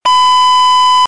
local-text-tone_24933.mp3